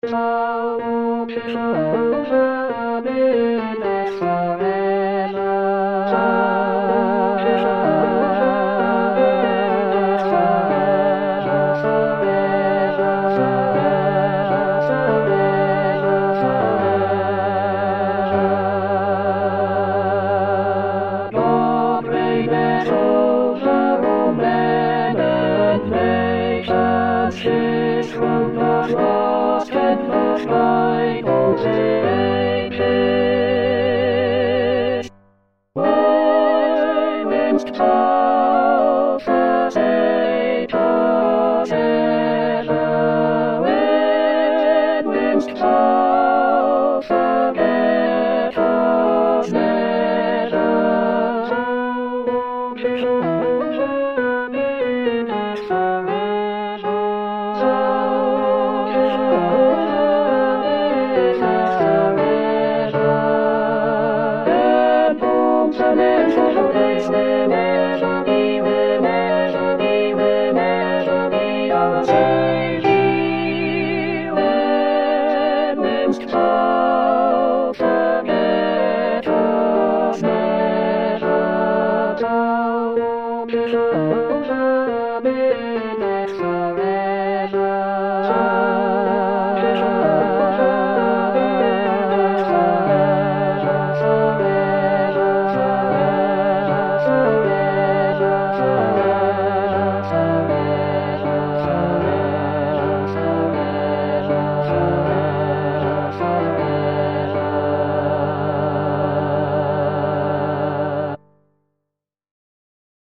Toutes les voix